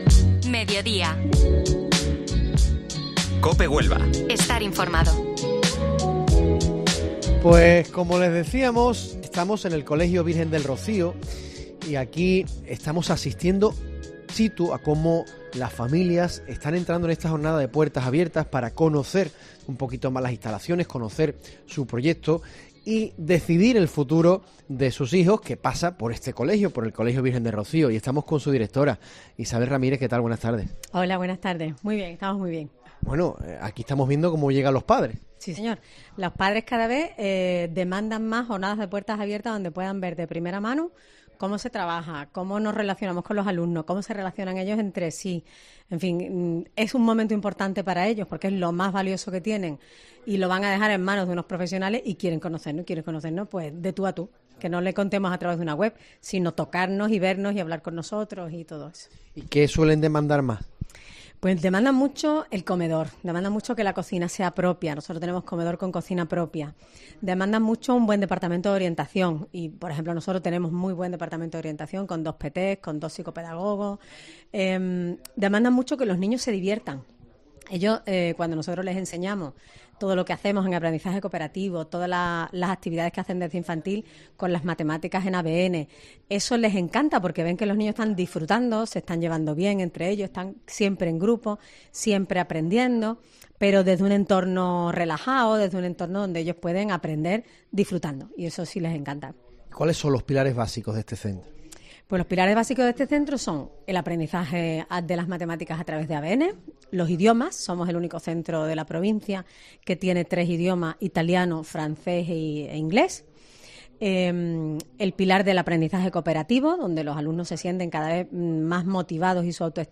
El Mediodía COPE Huelva de este martes se ha realizado desde las aulas del Colegio Virgen del Rocío donde hemos conocido su oferta académica así como los proyectos y actividades.